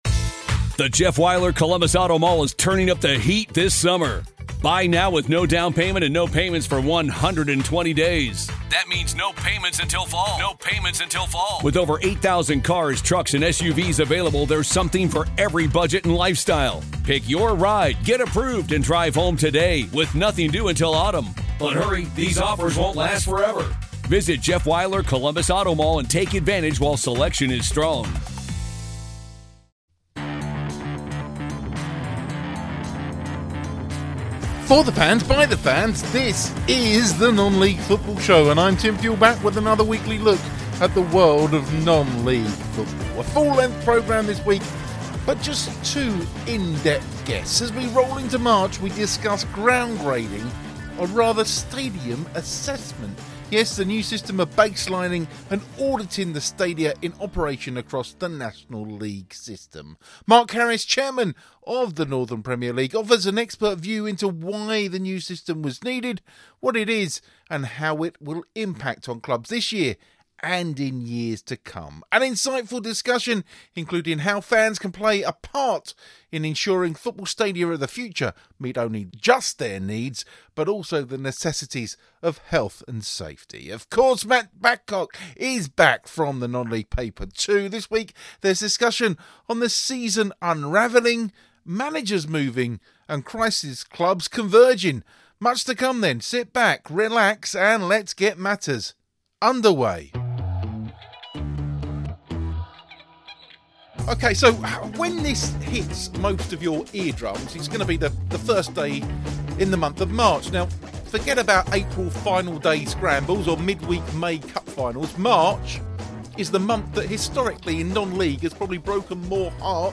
A full-length programme this week but just the two in-depth guests. As we roll into March we discuss ground grading or rather Stadium Assessment the new system of baselining and auditing the stadia in operation across the National League System.